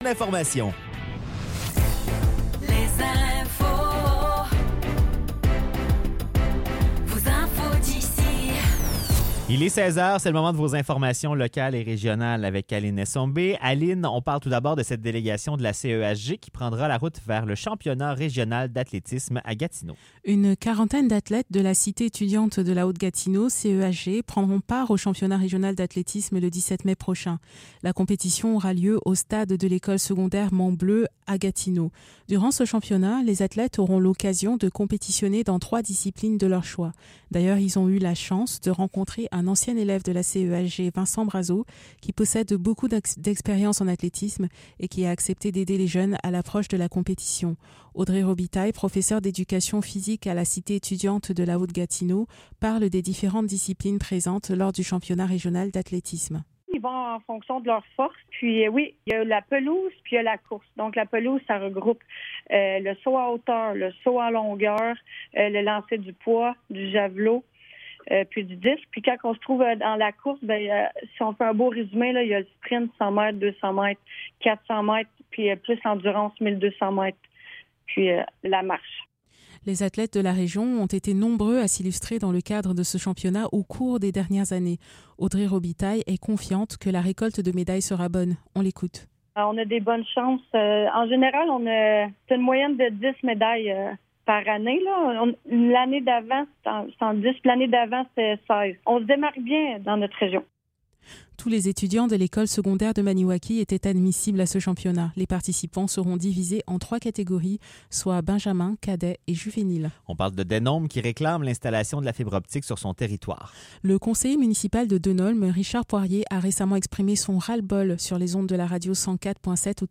Nouvelles locales - 2 mai 2024 - 16 h
Retrouvez les nouvelles locales du mercredi 2 mai 2024 de 16 h.